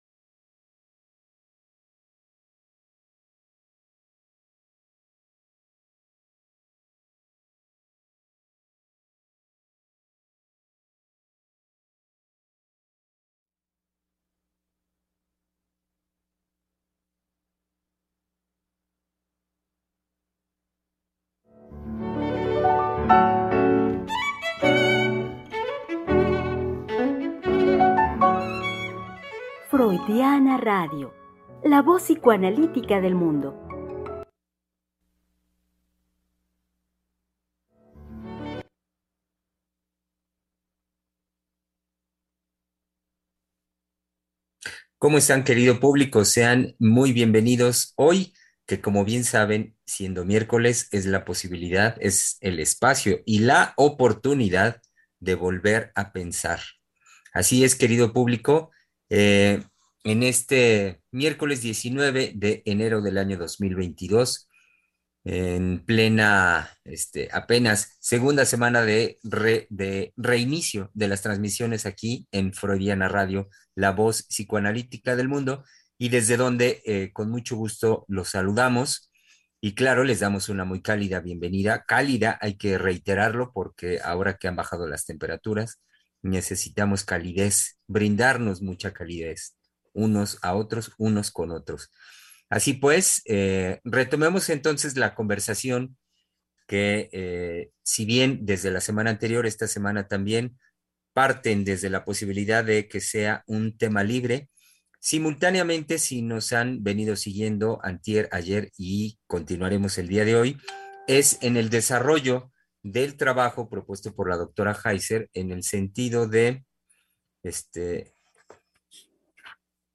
Programa transmitido el 19 de enero del 2022.